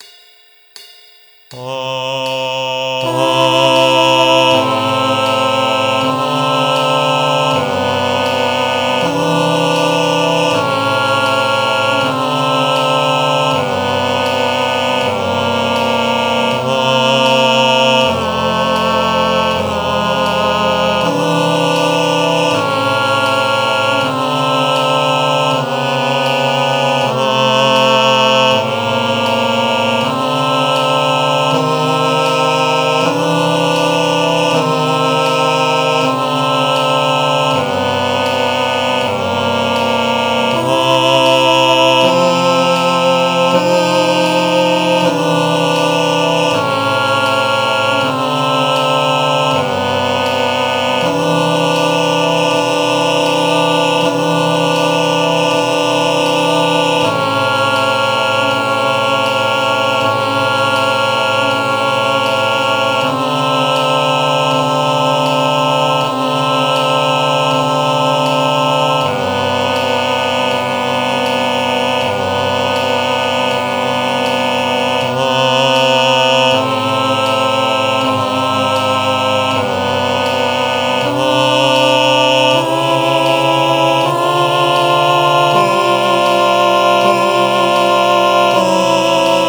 I’ve included files with a drone of the key center.
Alto and Bass Only with Drone
MP3 with Alto and Bass only with Cymbal